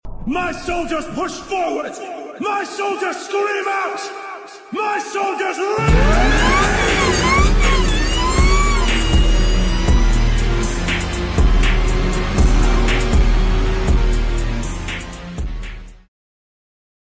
This burnout made everyone turn